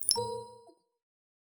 Futuristic Sounds (30).wav